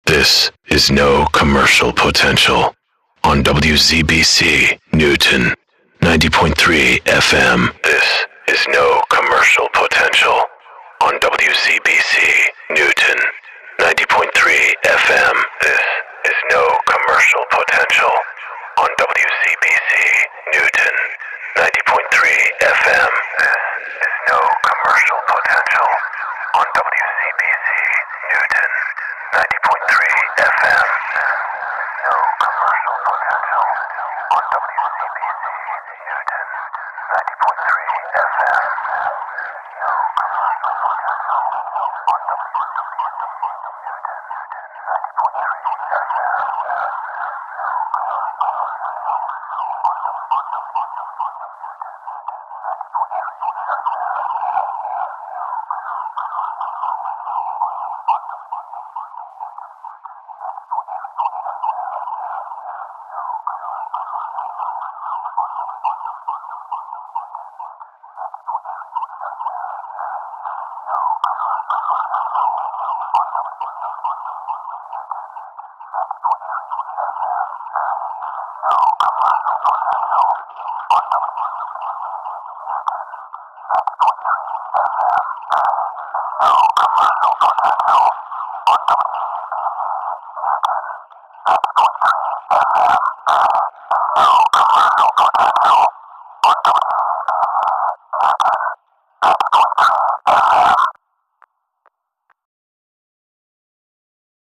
NCP Legal ID voice only decay Loop